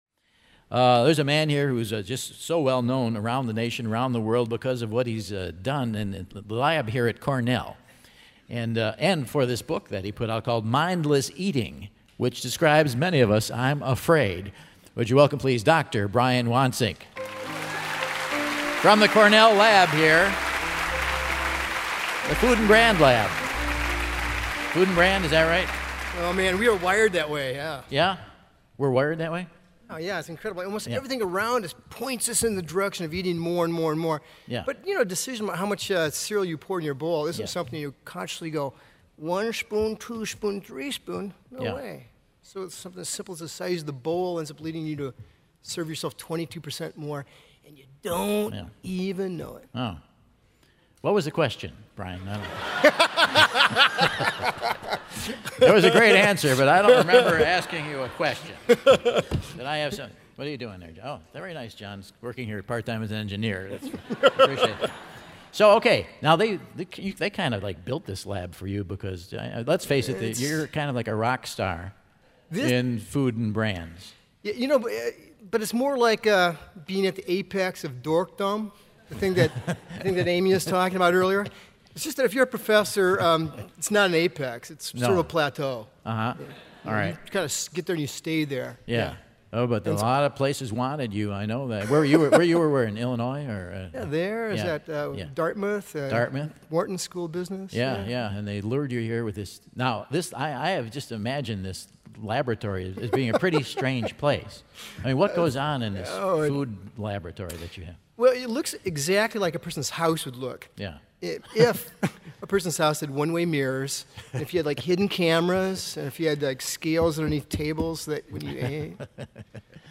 Mindless Eating author and Ithaca native, Brian Wansink, cleans his plate on stage with Michael.